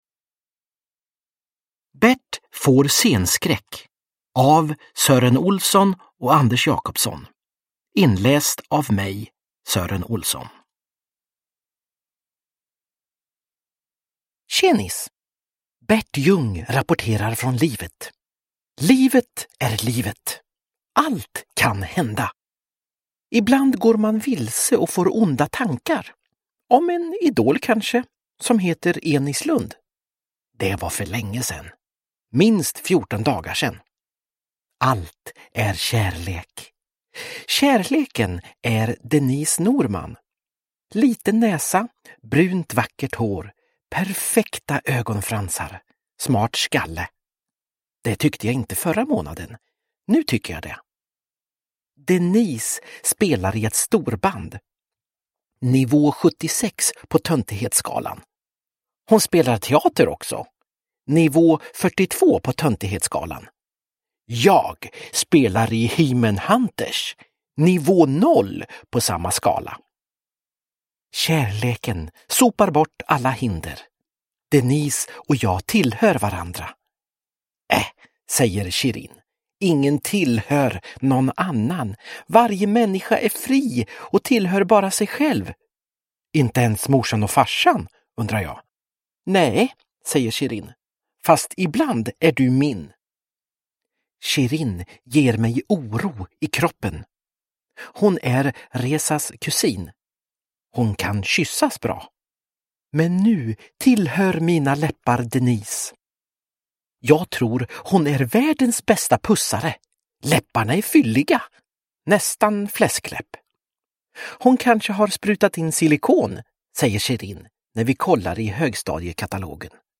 Bert får scenskräck – Ljudbok – Laddas ner
Uppläsare: Sören Olsson